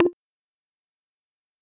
На этой странице собраны разнообразные звуки ударов и урона: резкие атаки, тяжёлые попадания, критические удары.